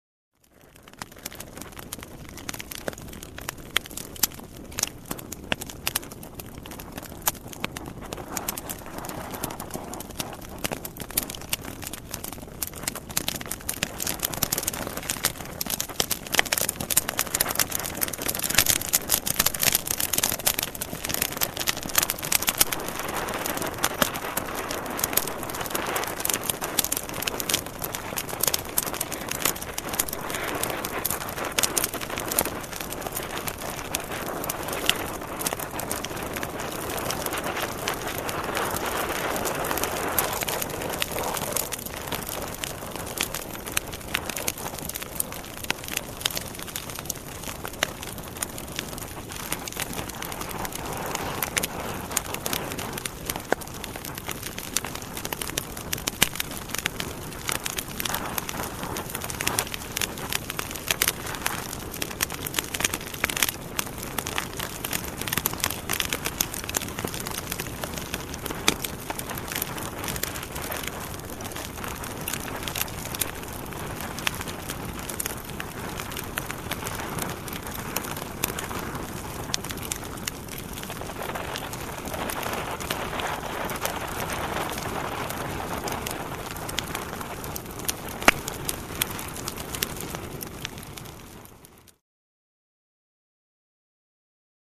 焚き火、濡れた木のヒスノイズ、燃えかすのパチパチ音、パチパチ音 無料ダウンロードとオンライン視聴はvoicebot.suで